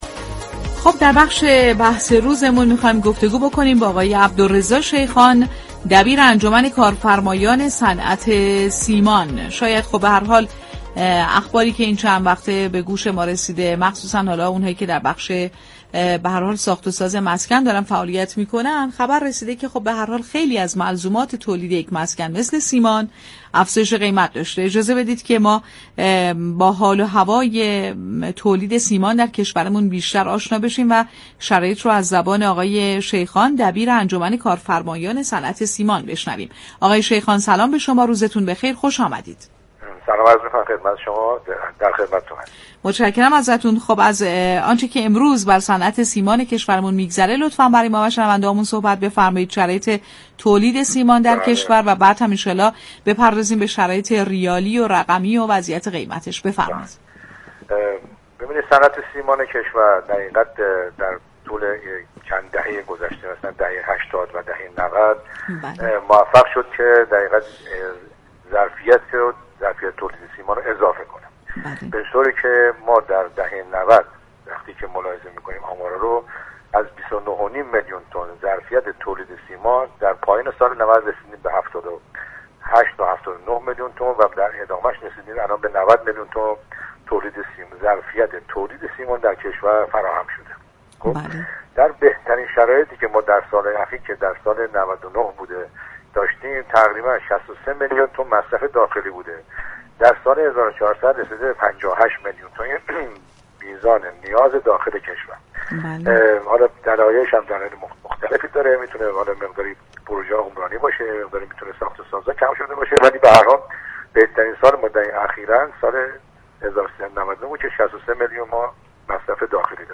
در گفتگو با بازار تهران رادیو تهران